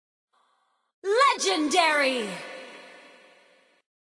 sounds of mobile legends bang sound effects free download